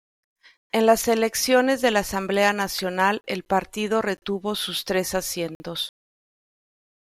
Pronounced as (IPA) /paɾˈtido/